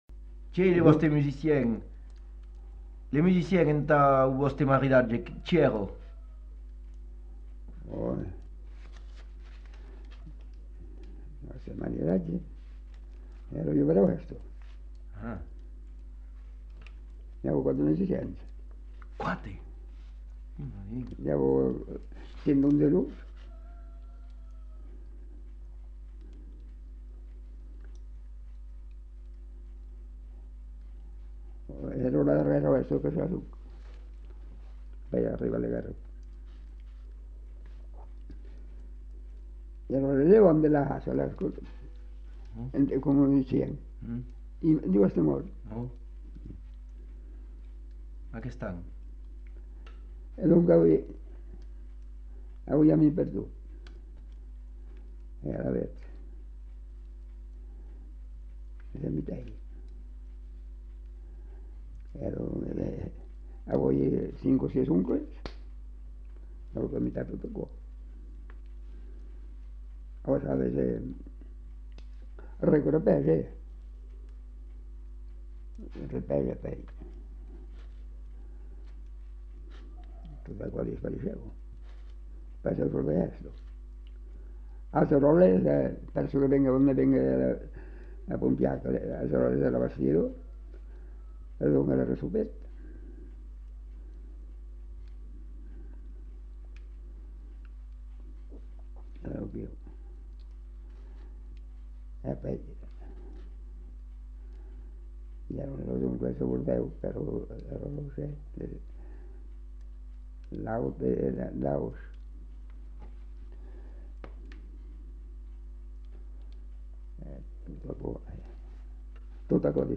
Genre : témoignage thématique